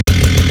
sfx_chainsaw_idle_3.wav